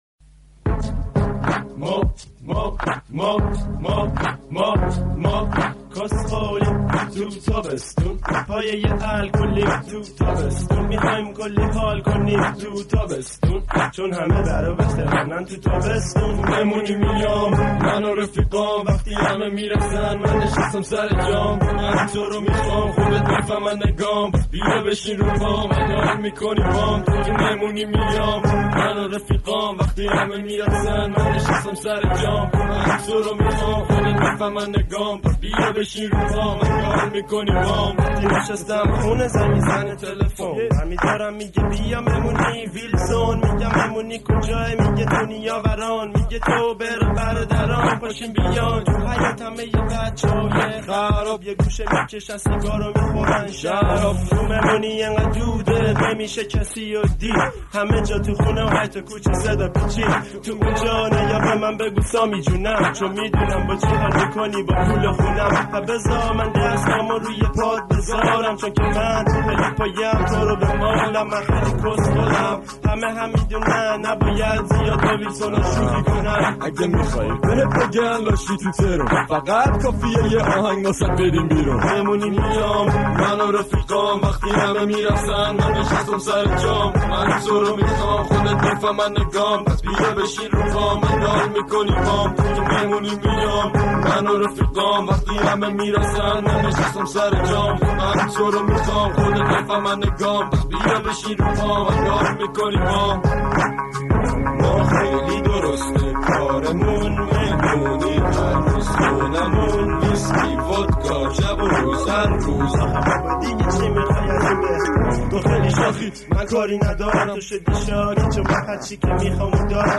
رپ فارسی